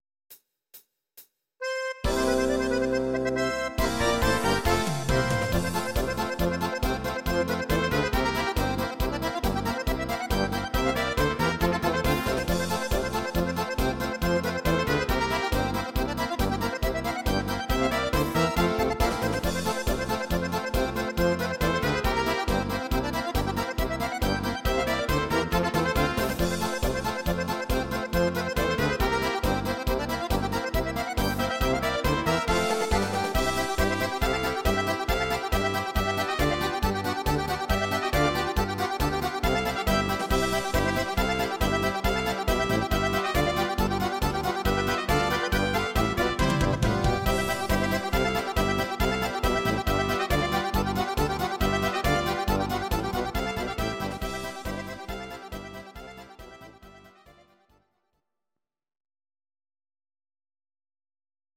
These are MP3 versions of our MIDI file catalogue.
Please note: no vocals and no karaoke included.
instr. Akkordeon